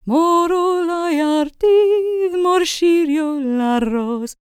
L CELTIC A31.wav